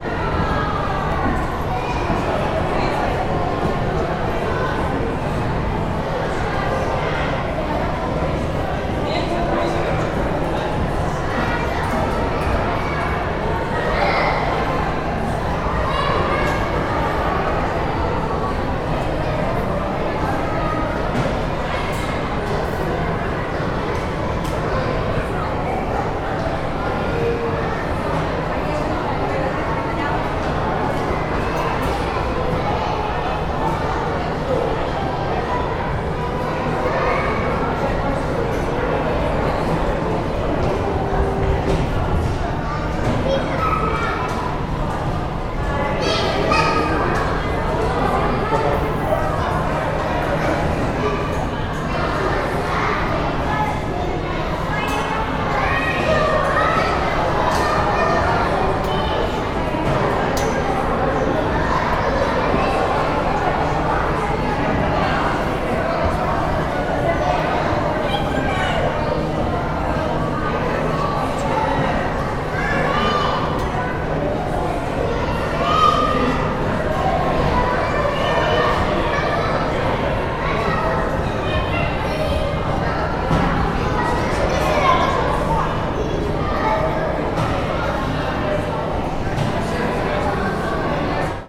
Здесь собраны атмосферные записи: от шороха шагов по паркету до приглушенных разговоров в выставочных залах.
Живая атмосфера естественно-исторического музея: дети оживленно болтают и ходят